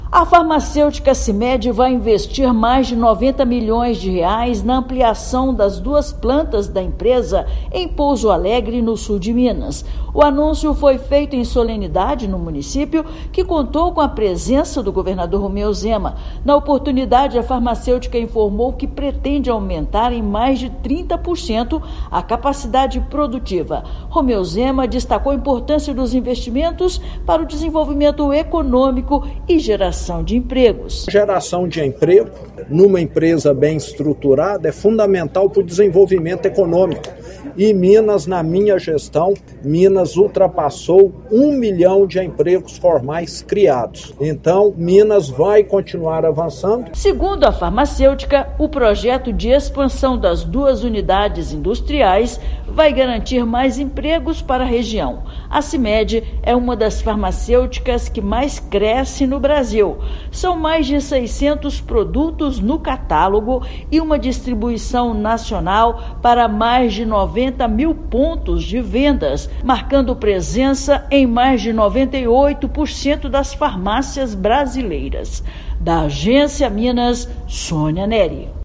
Cimed vai expandir suas fábricas e aumentar a atração de empregos em Minas. Ouça matéria de rádio.